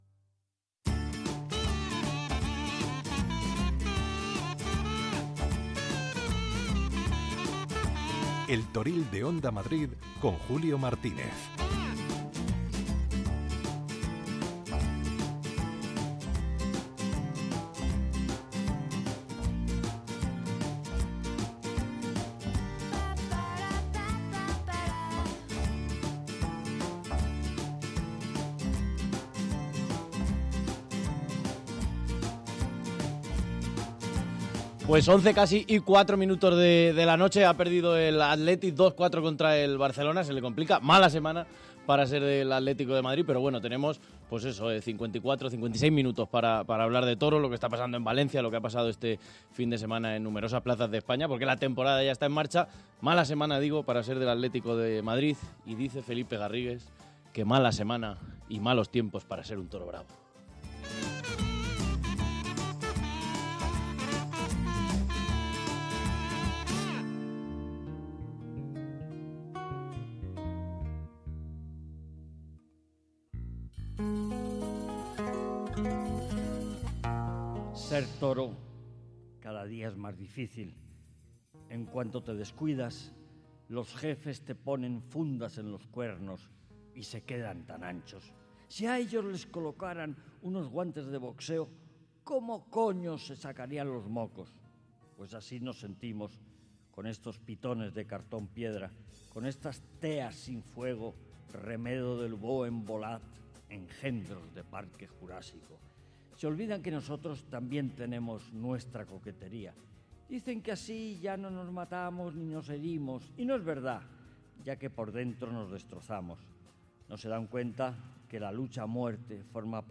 Habrá información pura y dura y entrevistas con los principales protagonistas de la semana.